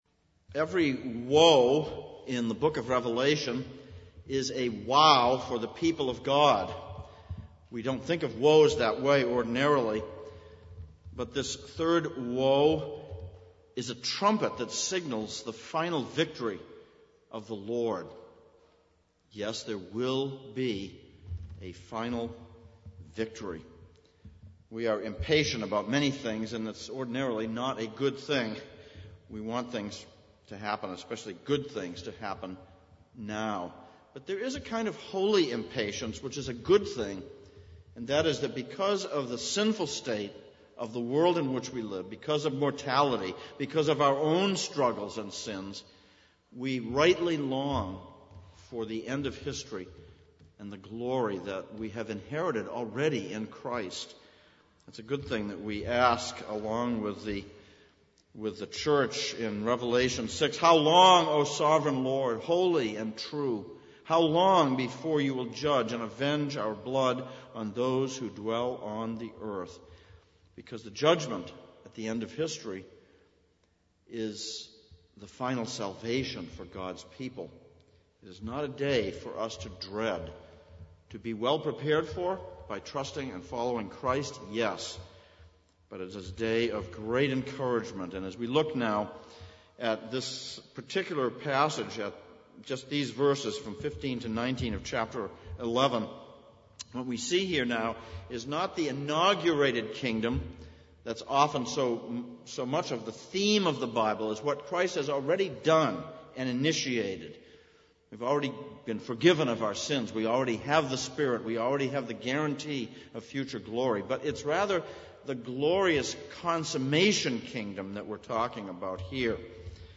Sermon
Service Type: Sunday Evening